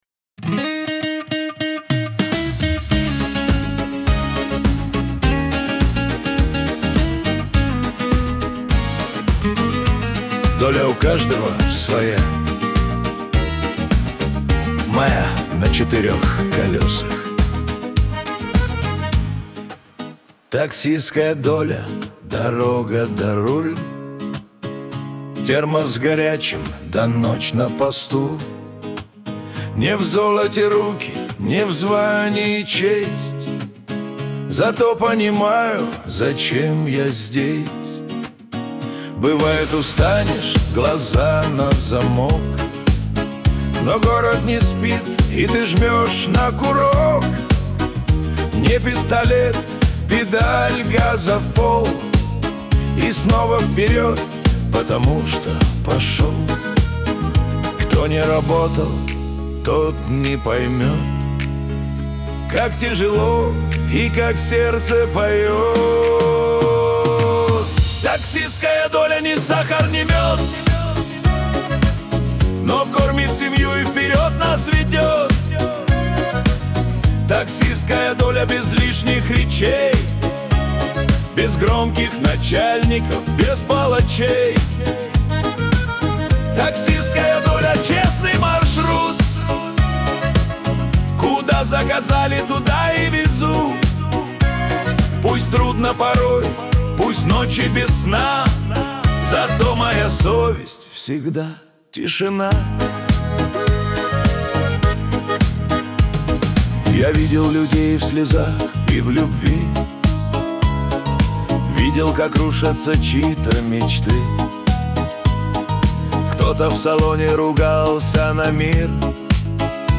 Лирика
ХАУС-РЭП , дуэт